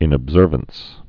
(ĭnəb-zûrvəns)